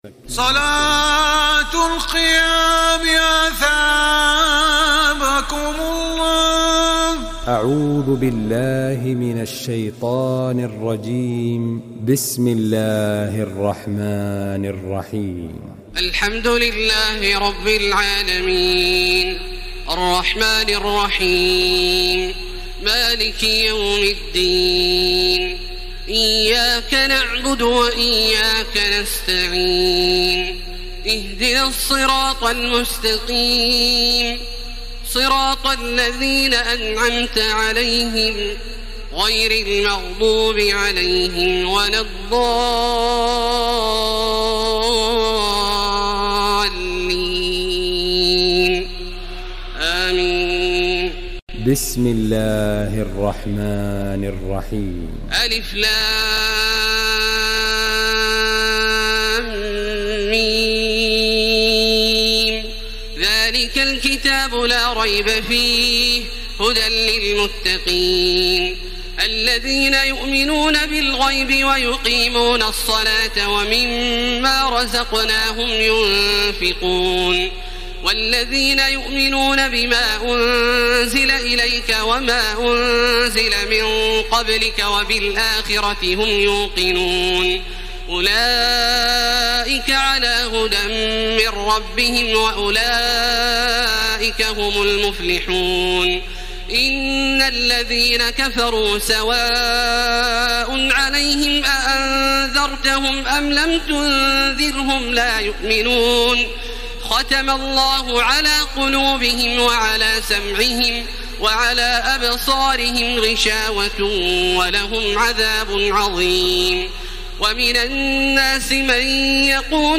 تراويح الليلة الأولى رمضان 1434هـ من سورة البقرة (1-86) Taraweeh 1st night Ramadan 1434 H from Surah Al-Baqara > تراويح الحرم المكي عام 1434 🕋 > التراويح - تلاوات الحرمين